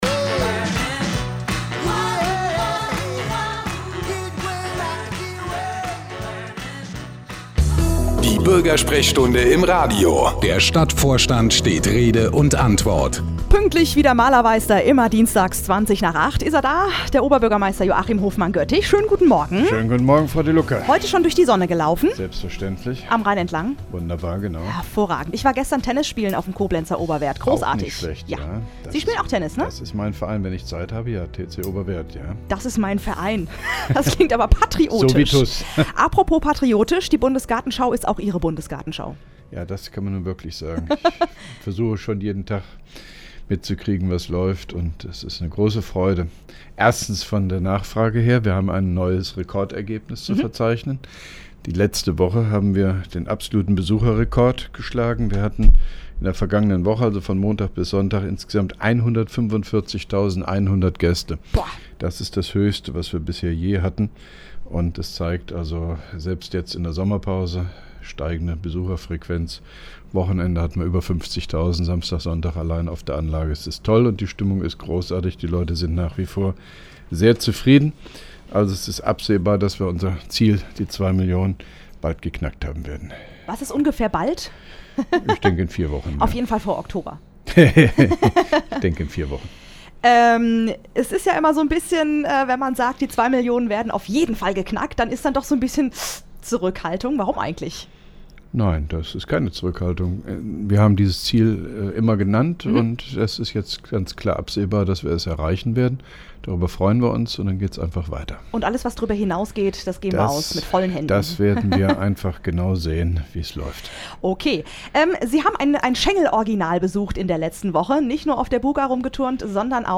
(1) Koblenzer Radio-Bürgersprechstunde mit OB Hofmann-Göttig 12.07.2011